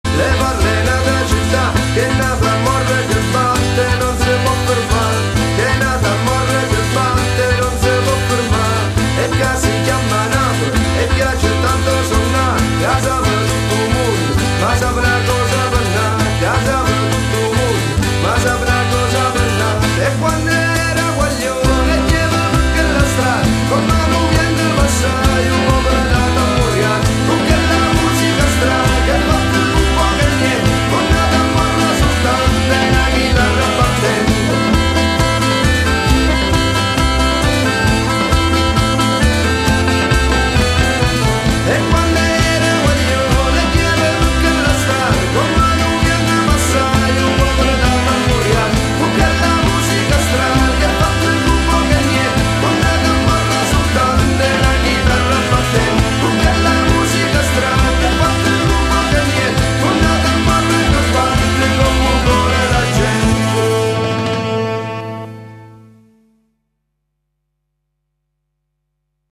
Voce e Chitarra
Fisarmonica
Percussioni